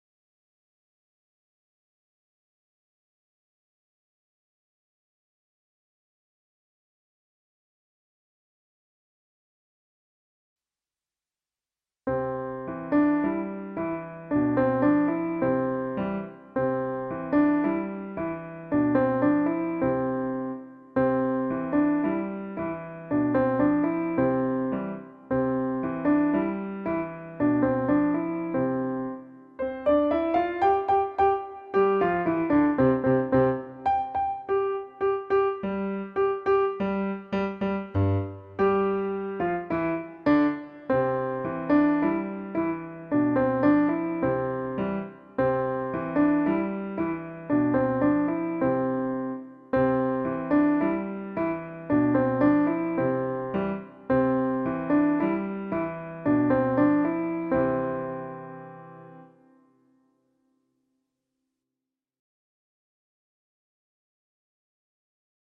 Traditional Folksong